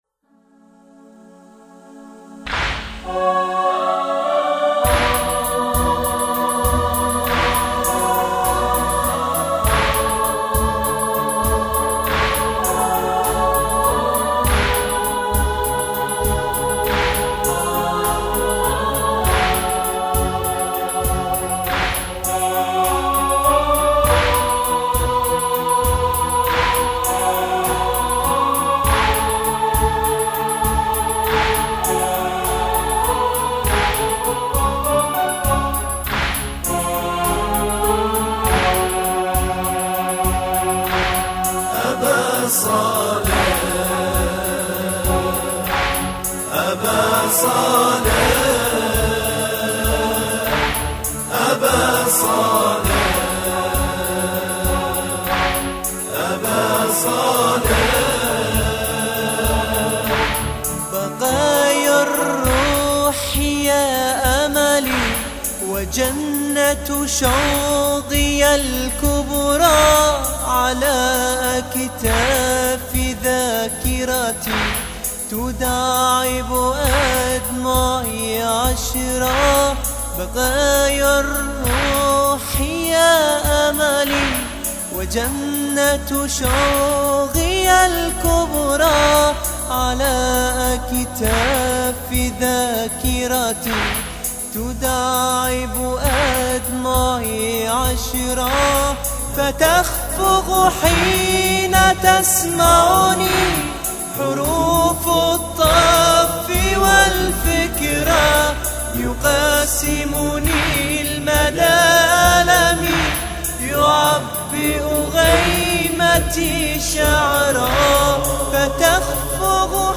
مداح اهل بیت